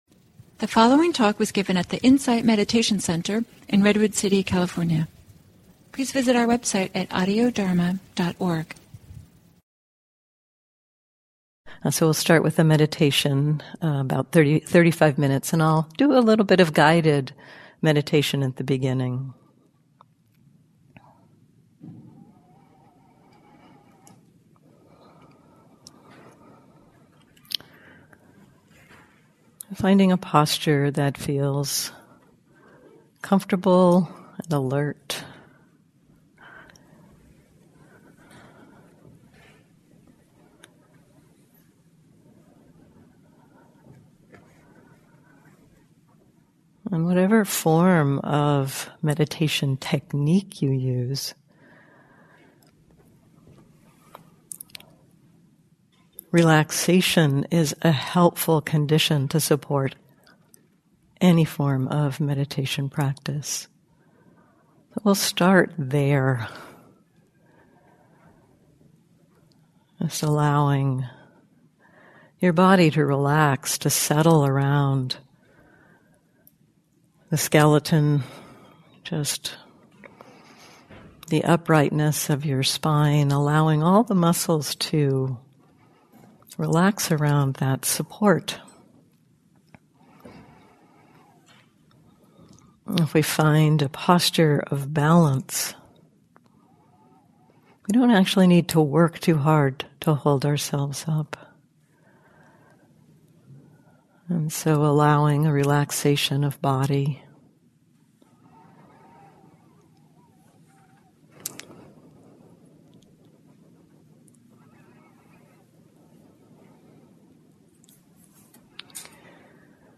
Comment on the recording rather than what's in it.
at the Insight Meditation Center in Redwood City, CA.